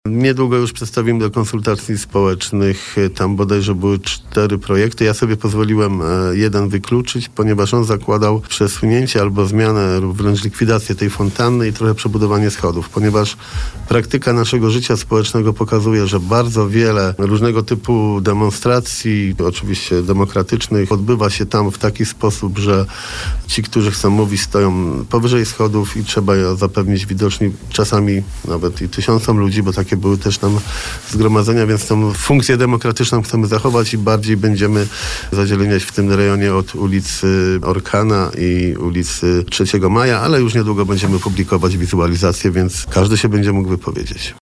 Tak mówił na naszej antenie prezydent Jarosław Klimaszewski, po tym jak miasto ogłosiło, że otrzymało na ten cel 467 tysięcy euro – bez konieczności zapewnienia wkładu własnego.